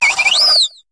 Cri de Papilord dans Pokémon HOME.